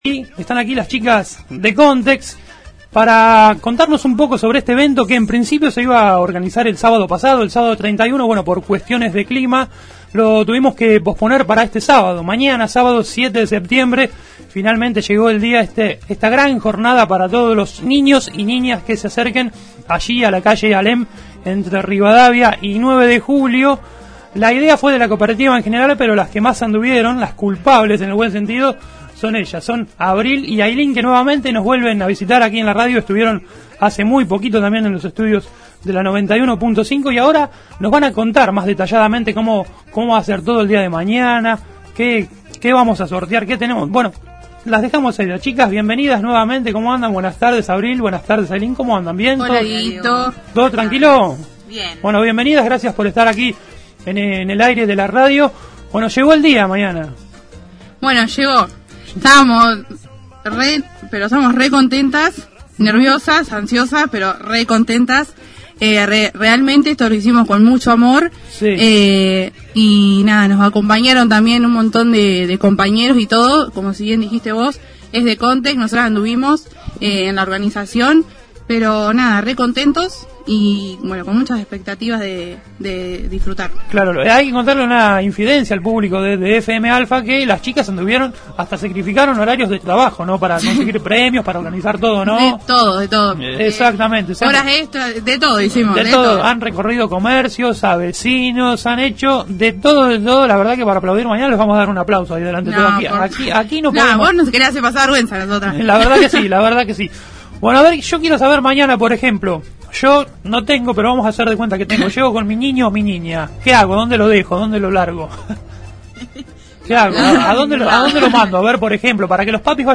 visitaron este viernes el programa «Apto para todo Público»